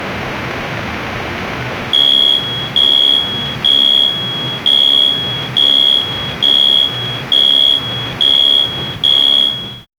Android Sound Effects - Free AI Generator & Downloads